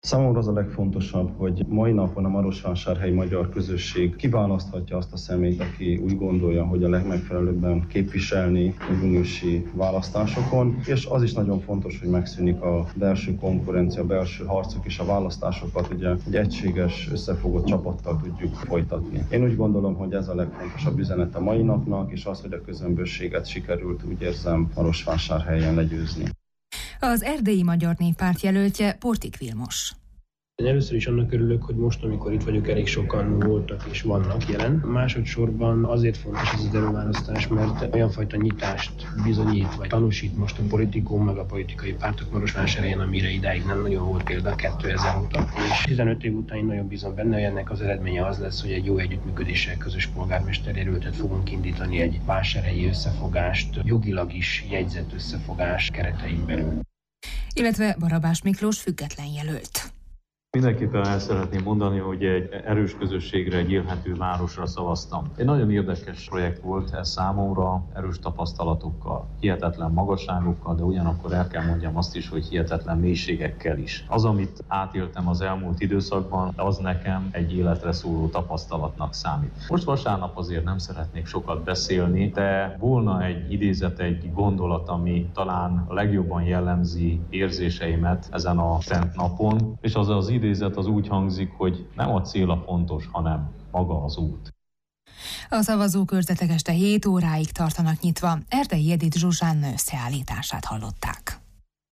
A három jelöltet kérdeztük!